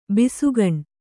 ♪ bisugaṇ